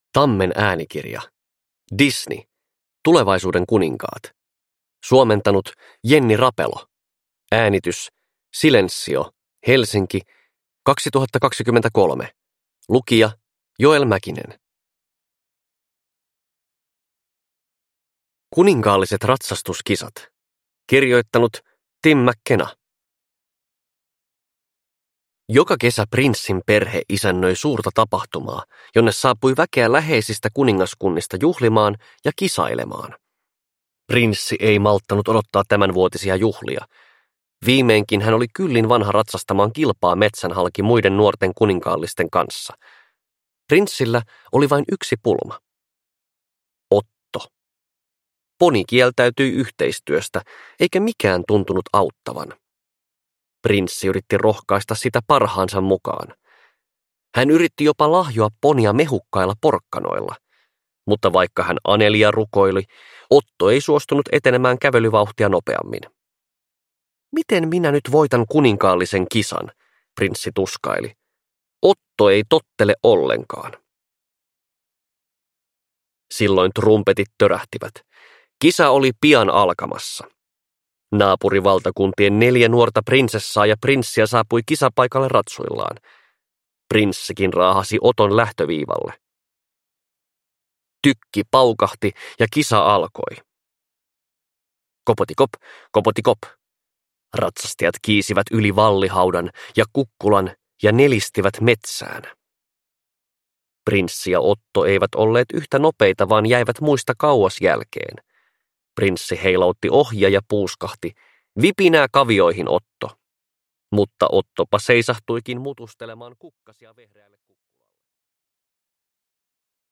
Disney. Tulevaisuuden kuninkaat – Ljudbok